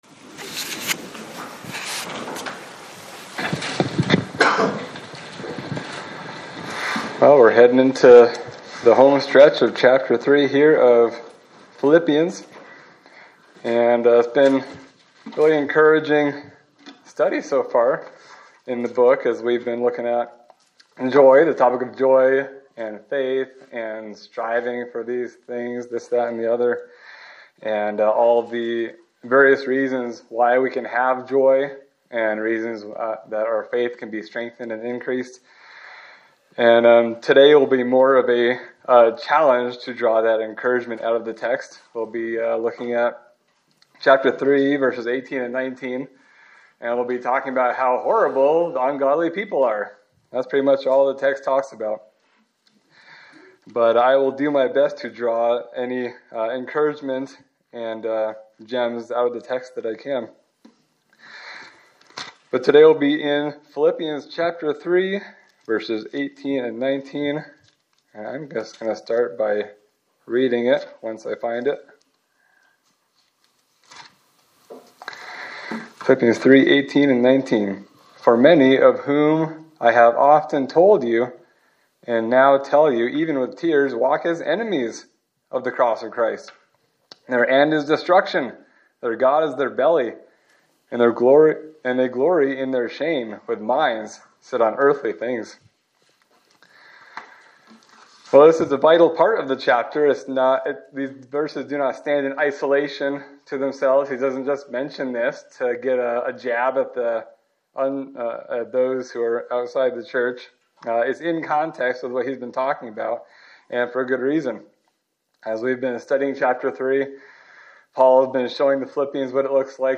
Sermon for November 16, 2025
Service Type: Sunday Service